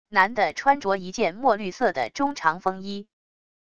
男的穿着一件墨绿色的中长风衣wav音频生成系统WAV Audio Player